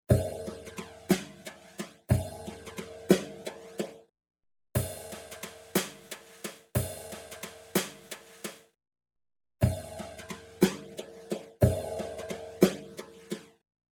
渦巻くクラシック・ロータリーサウンド
Rotary Mod | Brush Kit | Preset: Auto Fast Slow Rotary
Rotary-Eventide-Brush-Kit-Auto-Fast-Slow-Rotary-Mix90.mp3